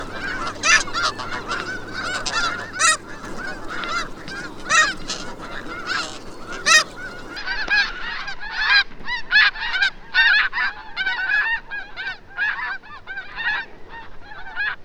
Snow Goose
Ganso
Anser caerulescens